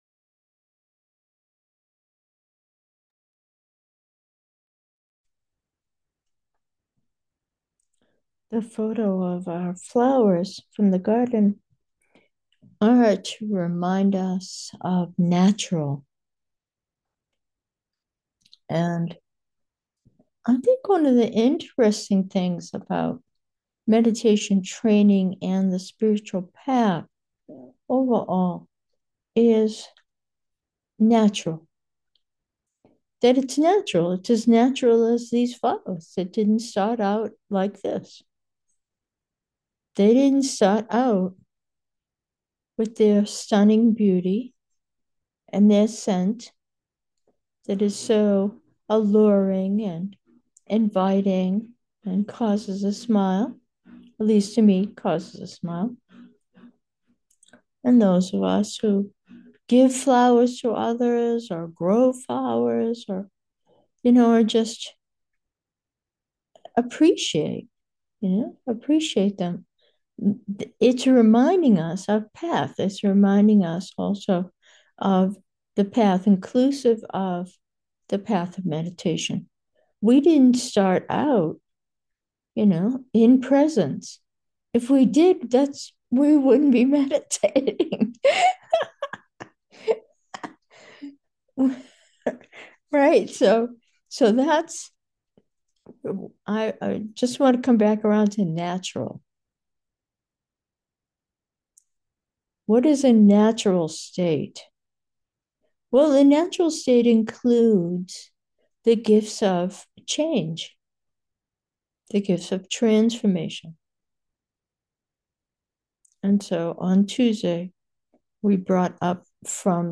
Meditation: alchemy 2, natural